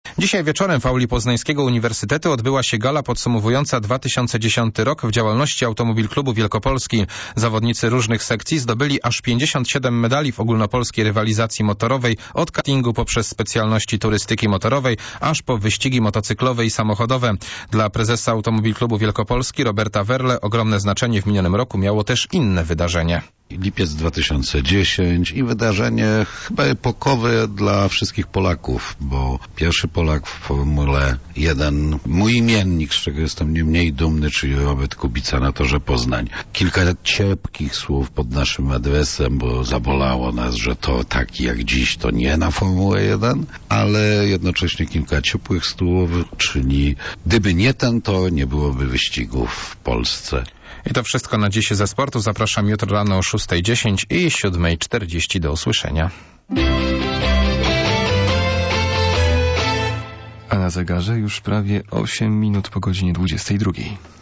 RADIO MERKURY - Wywiad